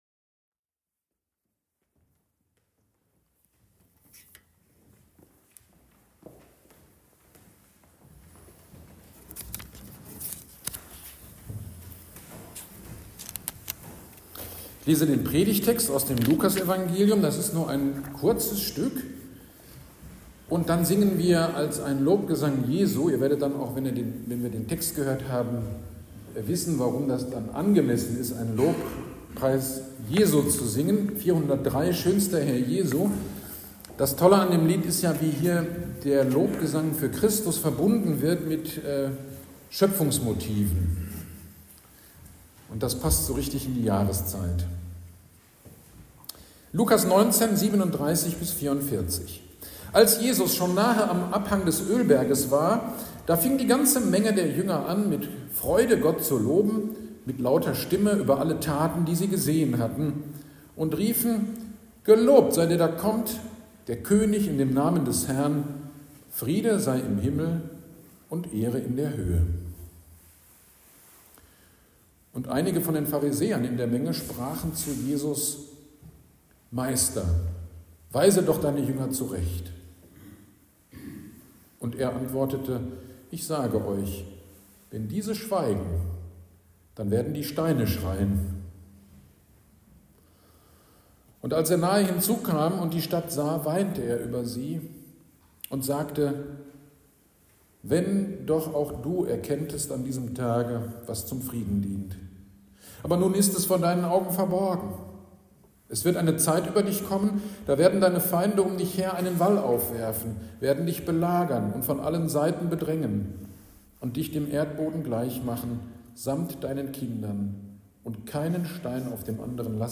GD am 18.05.25 (Kantate) Predigt zu Lukas 19, 37-44 - Kirchgemeinde Pölzig